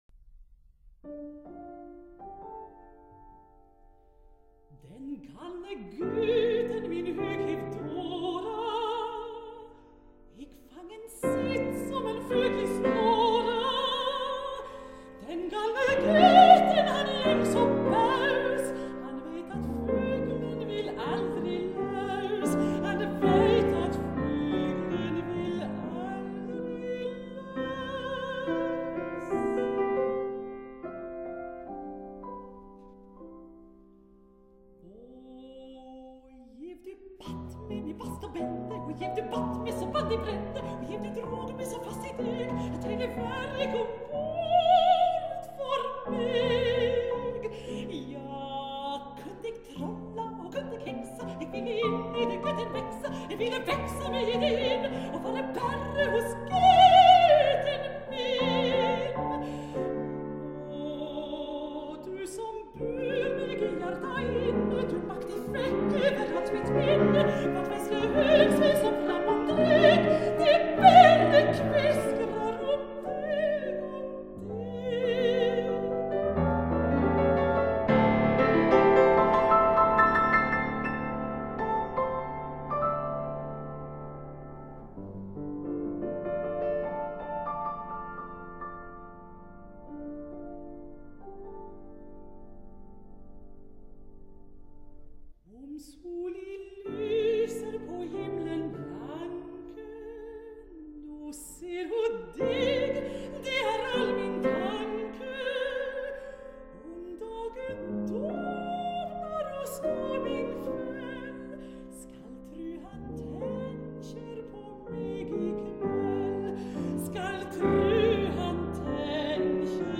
音樂類型：古典音樂
女中音。